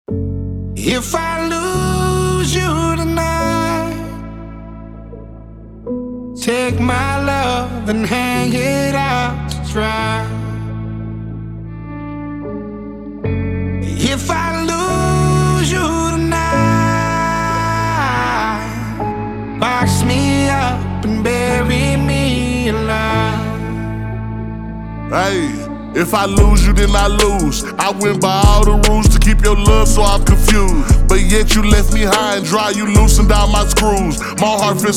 Hip-Hop Rap Country
Жанр: Хип-Хоп / Рэп / Кантри